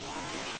cardboard_slide.ogg